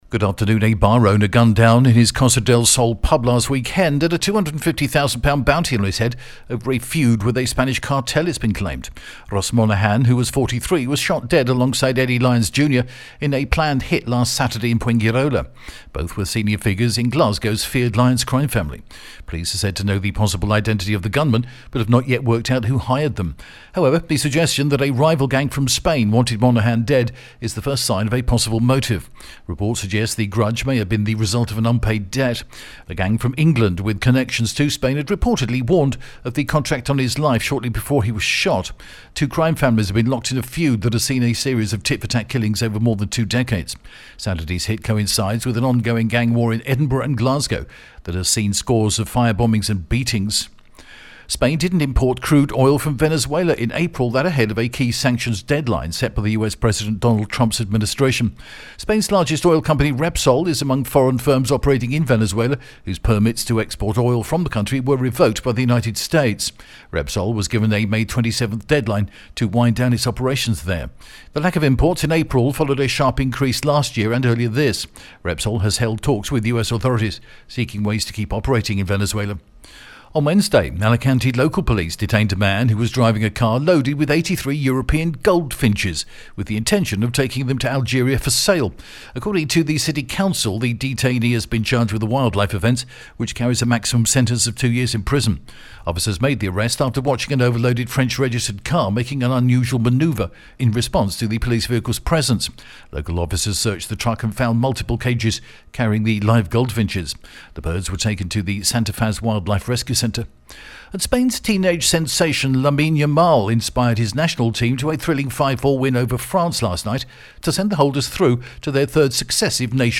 The latest Spanish news headlines in English: June 6th 2025